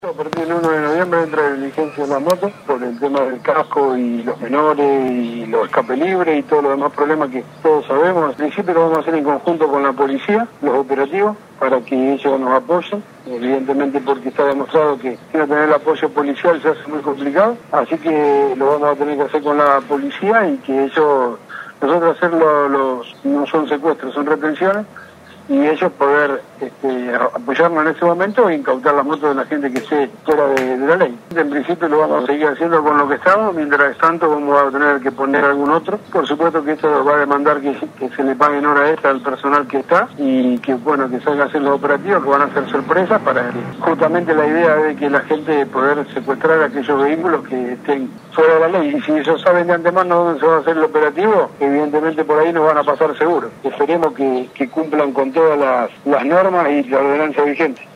El presidente comunal de la localidad Gabriel Gentili dio más detalles de estas normas, indicando que se harán operativos sorpresa junto a la Policía: